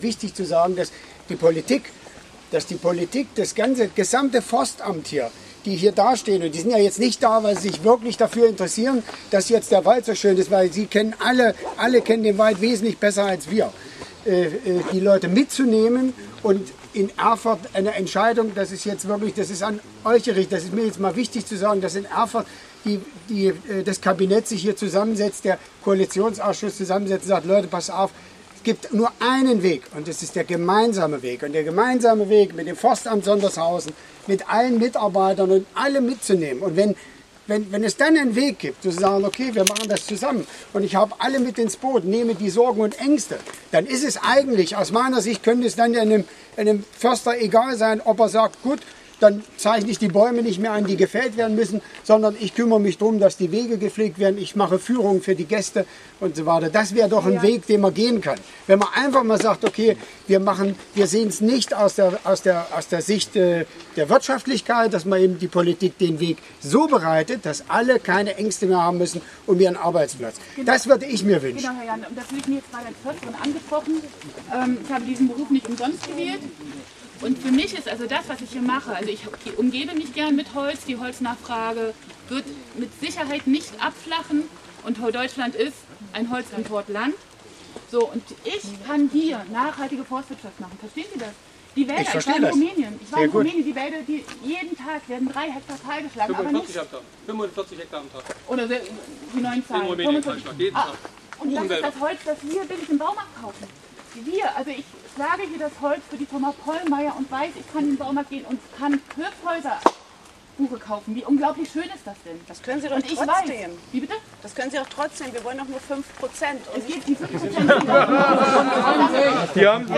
Auch eine Bürgerin aus Bendeleben klinkte sich in die Ansprache ein und forderte einen weiteren forstwirtschaftlichen Betrieb am Possen.
Ansprache